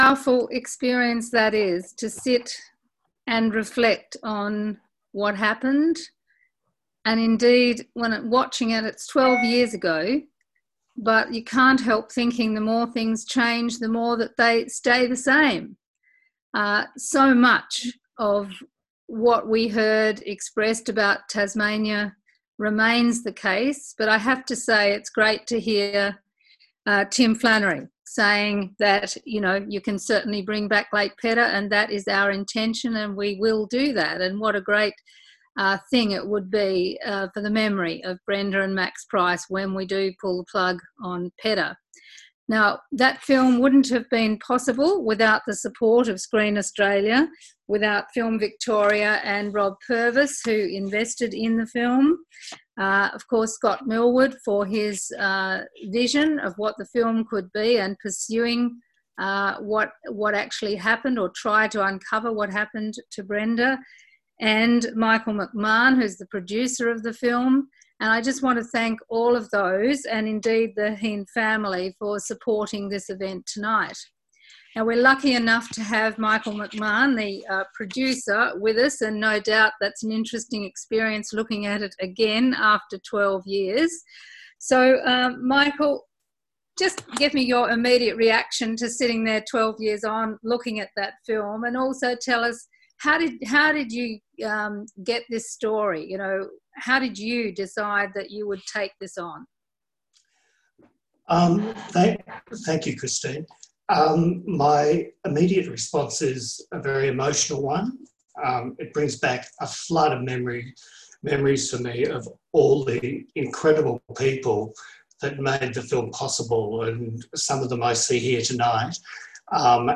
Thank you to all who made it along to the Duke for the discussion on climate initiatives here in Tasmania that the next Federal government should jump on board with.